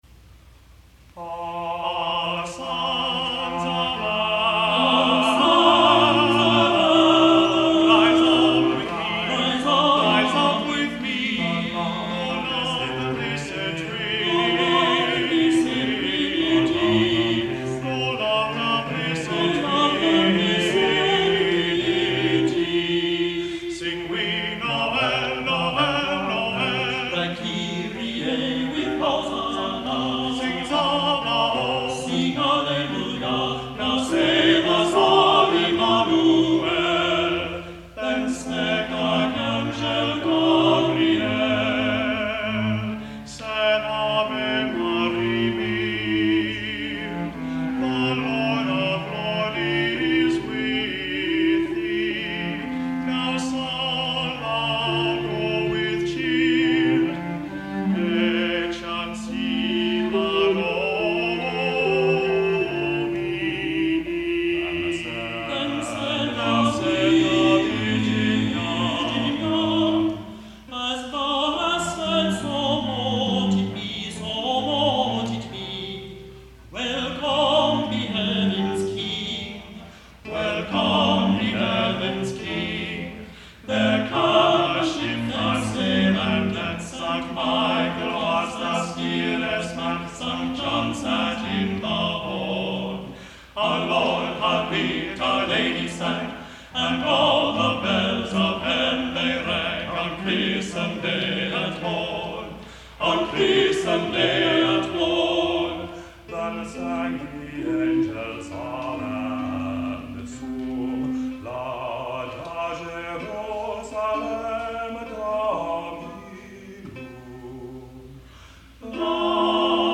Anonymous (Scottish)